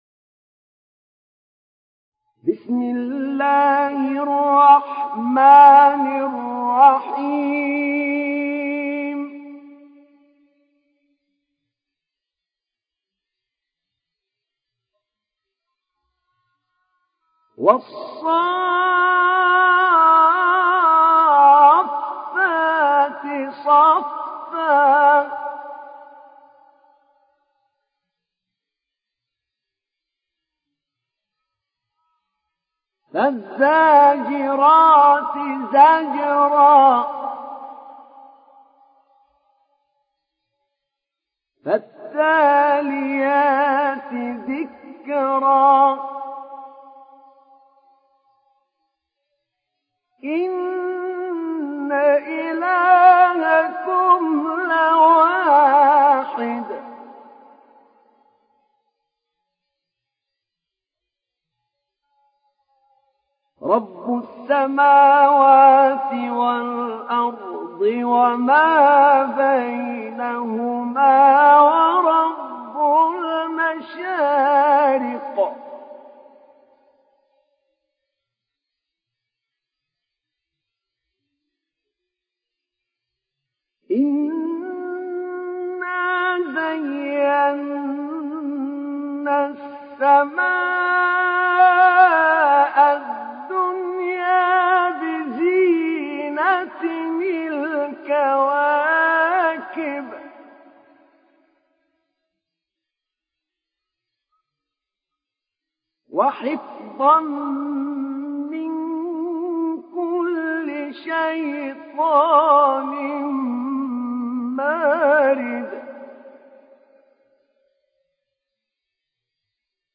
সূরা আস-সাফ্‌ফাত ডাউনলোড mp3 Ahmed Naina উপন্যাস Hafs থেকে Asim, ডাউনলোড করুন এবং কুরআন শুনুন mp3 সম্পূর্ণ সরাসরি লিঙ্ক